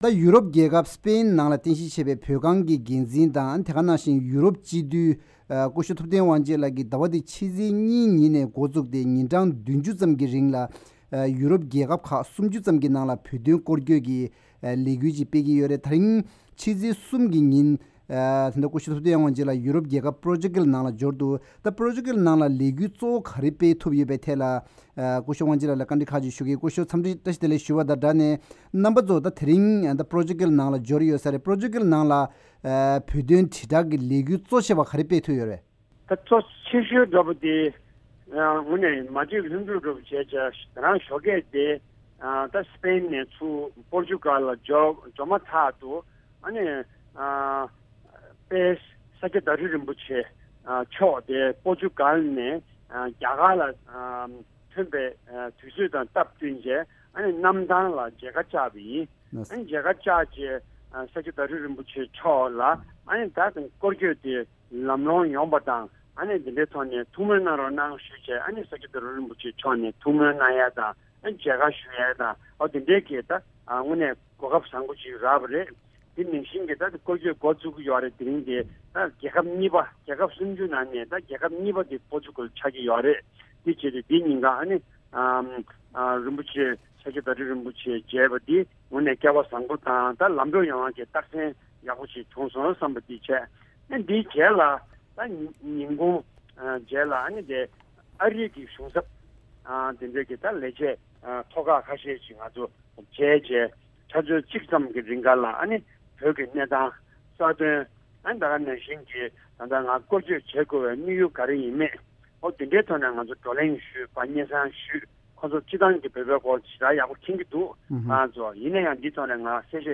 གནས་དྲི་ཞུས་པ་ཞིག་གསན་གྱི་རེད།